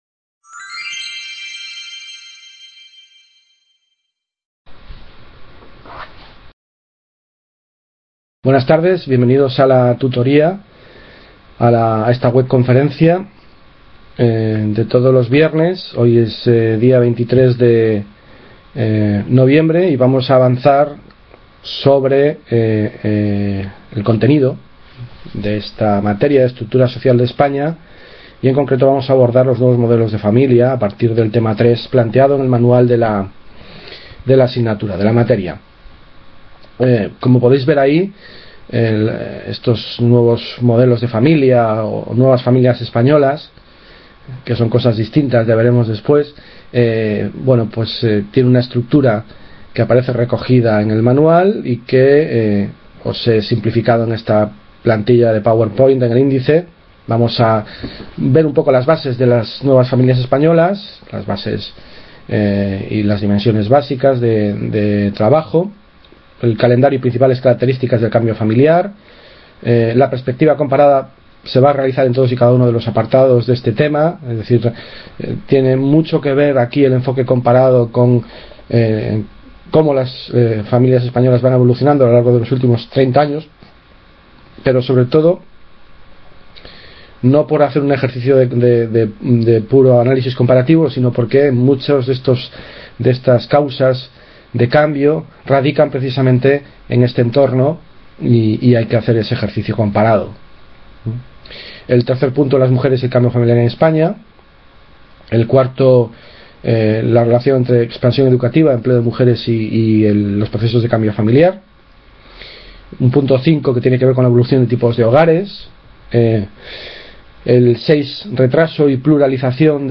Webconferencia, Tema 3, Nuevas Familias, Estructura…